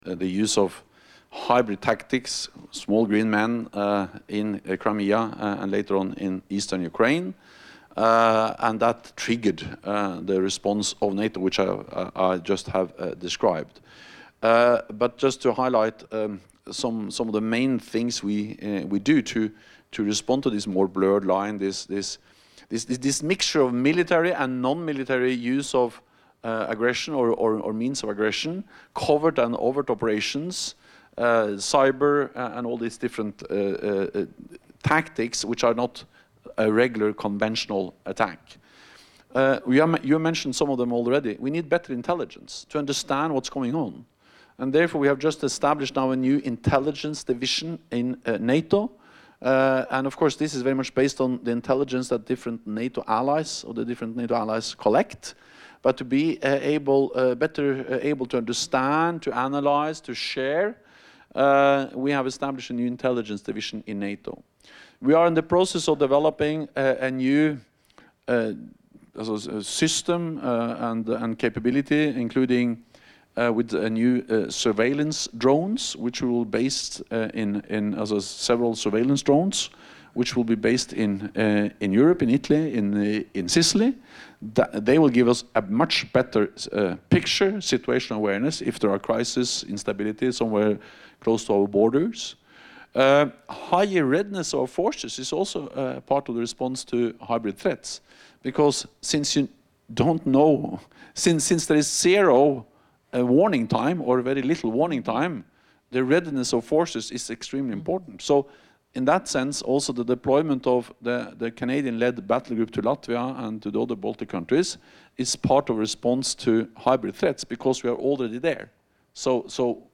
Remarks by NATO Secretary General Jens Stoltenberg at a Town Hall event at the University of Ottawa